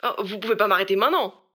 VO_ALL_Interjection_10.ogg